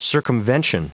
Prononciation du mot circumvention en anglais (fichier audio)
Prononciation du mot : circumvention